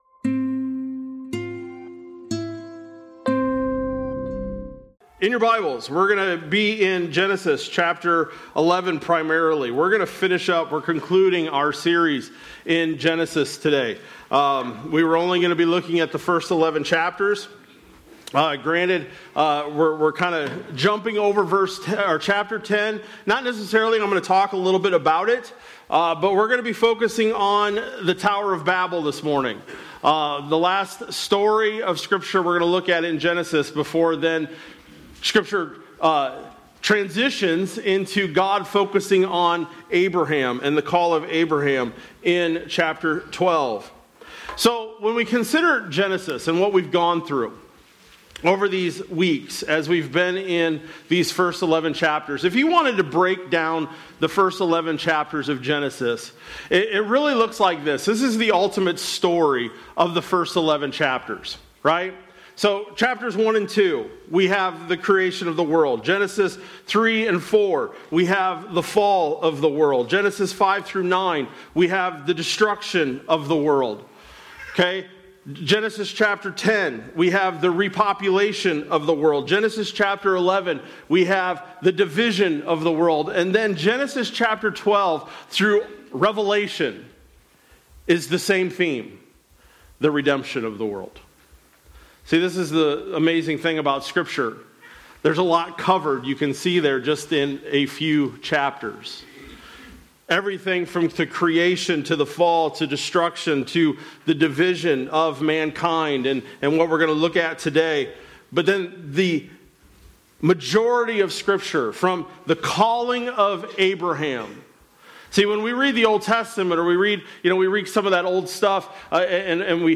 March-29-26-Sermon-Audio.mp3